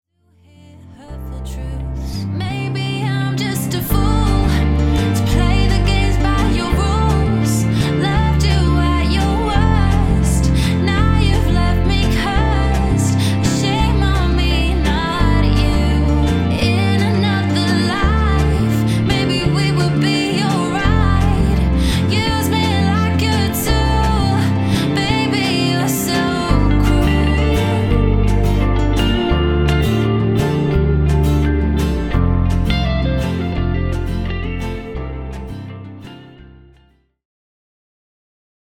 Pop/Dance